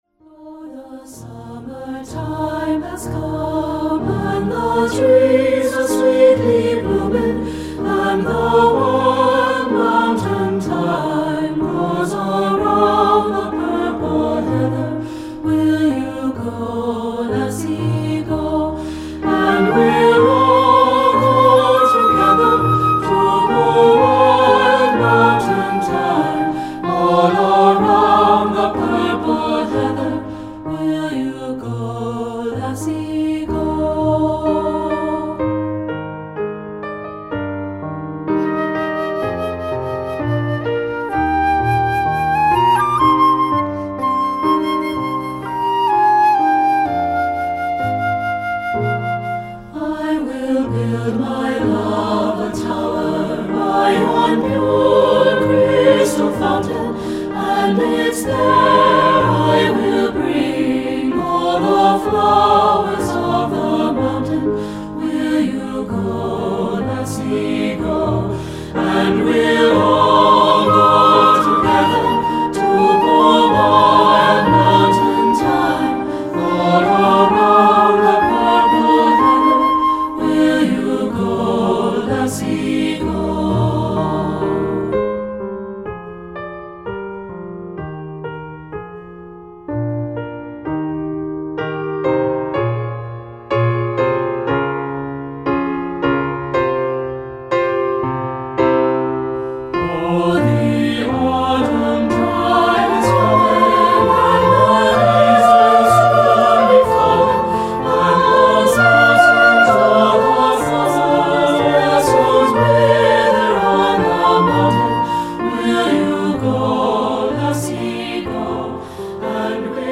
Choral Folk Multicultural
Scottish Folk Song
3 Part Mix